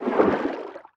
Sfx_creature_trivalve_swim_slow_02.ogg